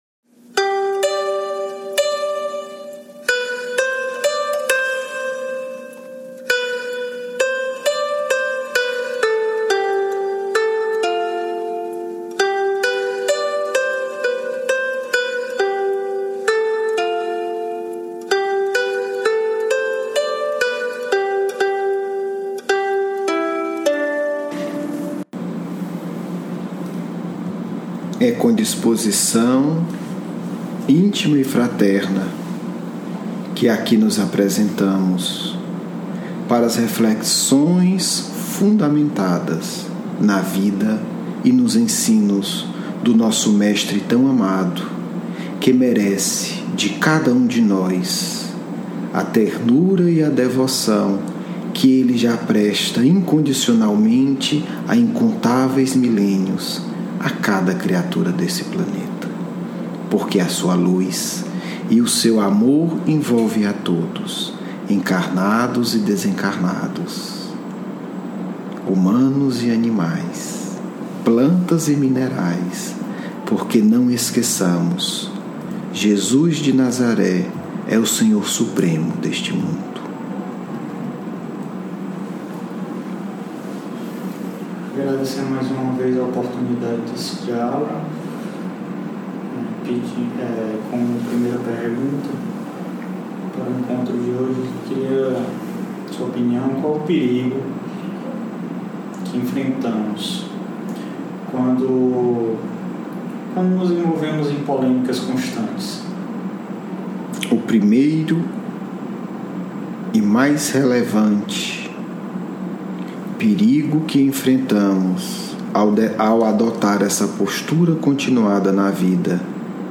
8 – Vício e Cristianismo, hoje Diálogo mediúnico